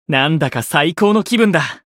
觉醒语音 なんだか最高の気分だ 媒体文件:missionchara_voice_442.mp3